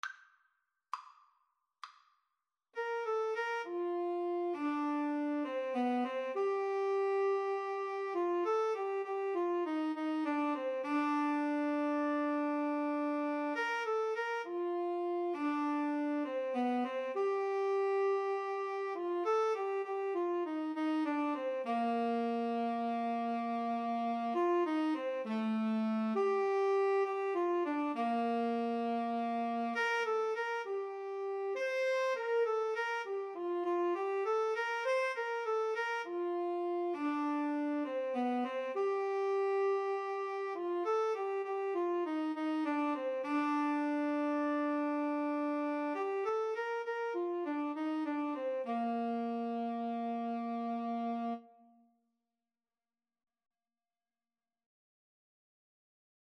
9/8 (View more 9/8 Music)
Moderato